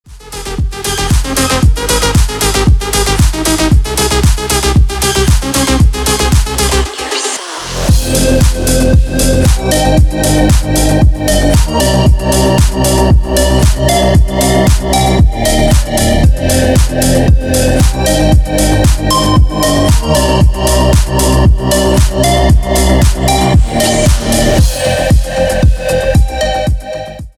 2025 » Новинки » Без Слов » Электроника Скачать припев